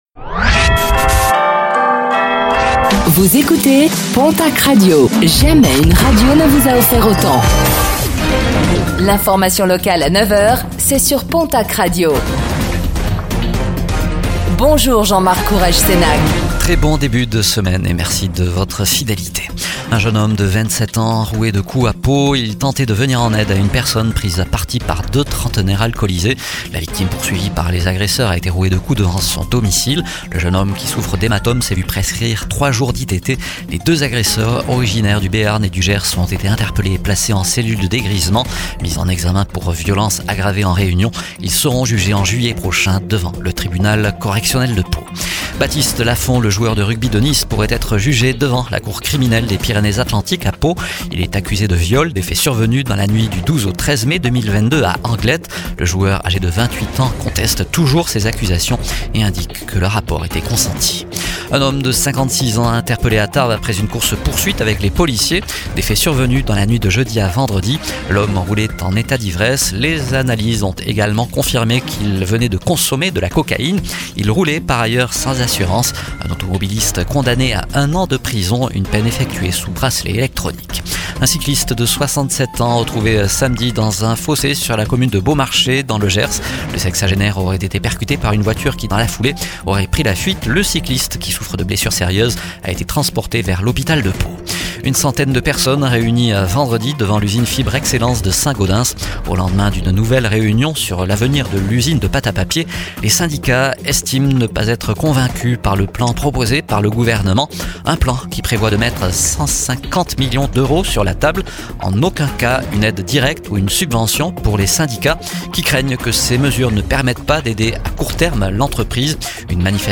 09:05 Écouter le podcast Télécharger le podcast Réécoutez le flash d'information locale de ce lundi 02 mars 2026